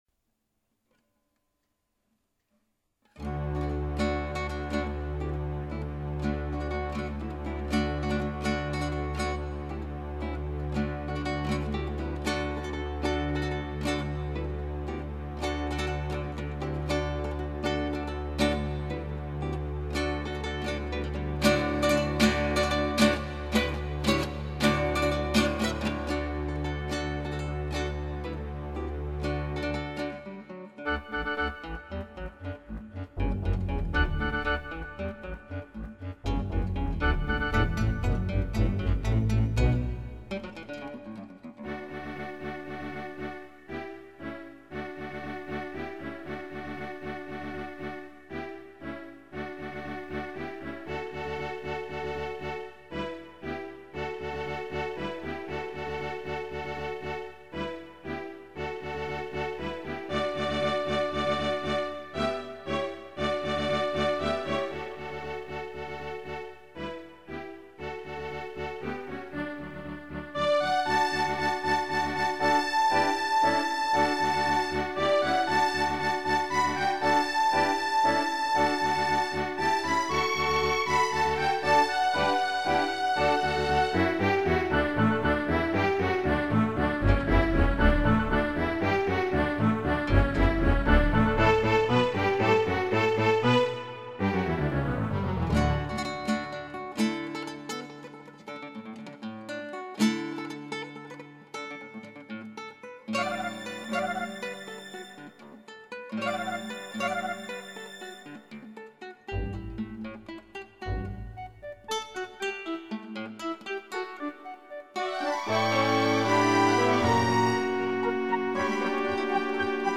ギターの自演をストリーミングで提供
弾けてないのう。 もう最初のラスゲアードからアボンだもんなぁ。